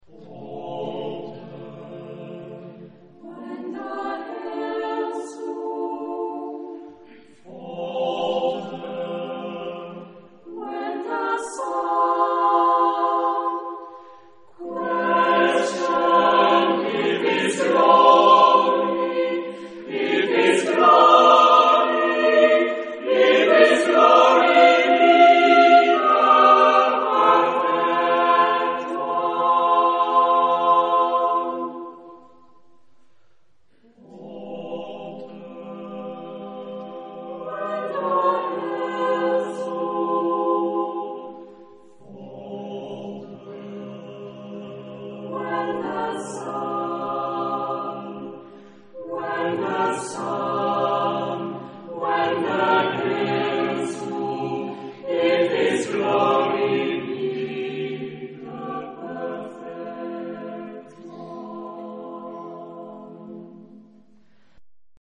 SATB (4 voix mixtes).
Profane. contemporain.